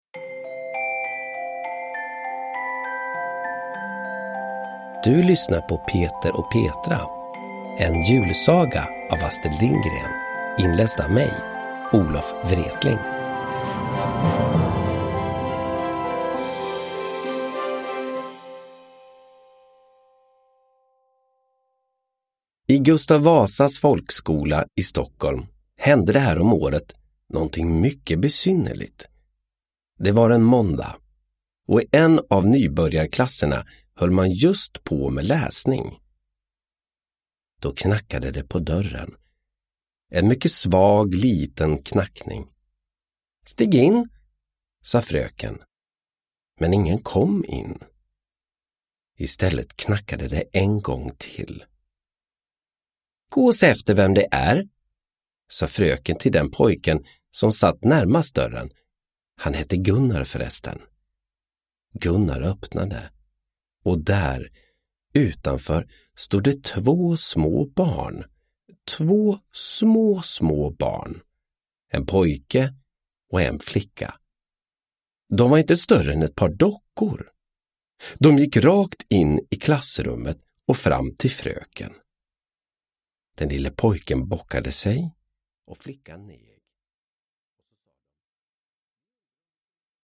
Peter och Petra – Ljudbok
Vänta på julen och lyssna på Olof Wretling när han läser en mysig julsaga av Astrid Lindgren.
Uppläsare: Olof Wretling